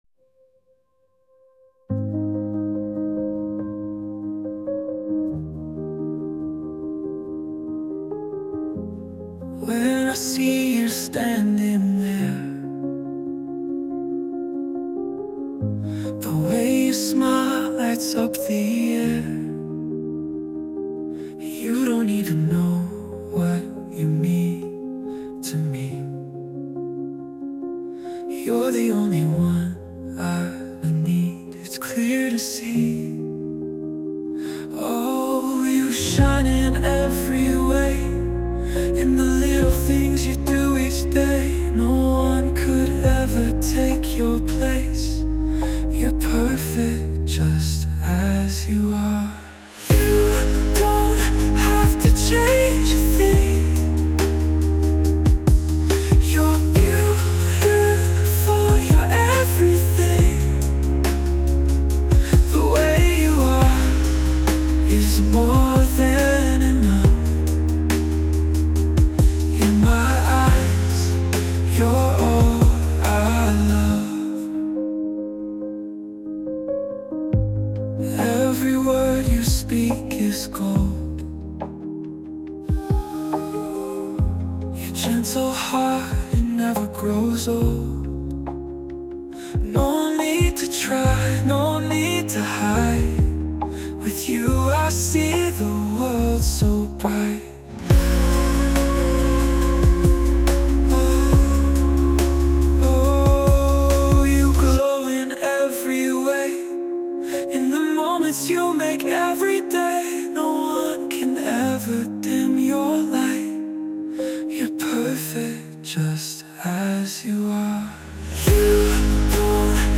洋楽男性ボーカル著作権フリーBGM ボーカル
男性ボーカル（洋楽・英語）曲です。
シンプルで感動的なメロディに乗せて、愛と感謝の気持ちを表現していて、特別な瞬間をさらに輝かせたい方にぴったりの一曲です！
リラックスした雰囲気の中で、癒しと感動を感じられるポップソングをぜひお楽しみください！